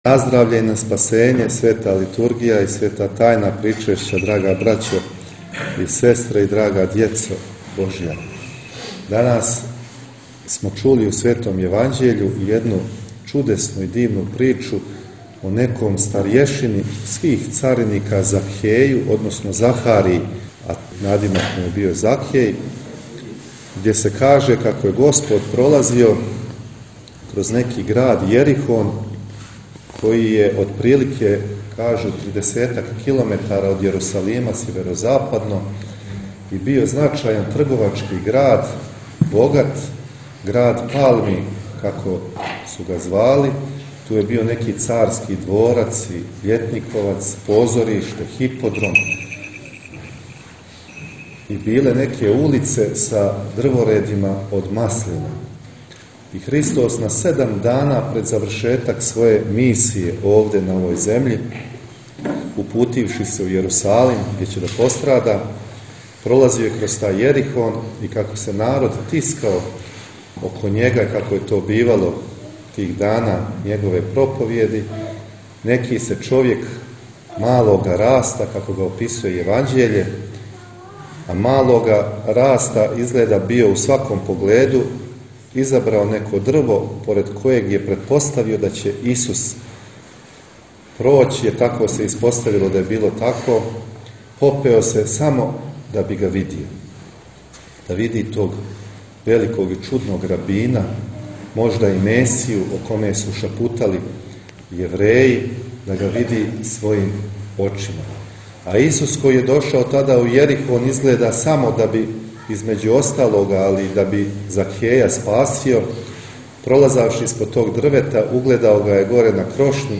Vladika Metodije bogoslužio je u hramu Svetog Nikole u nikšićkom naselju Dragova Luka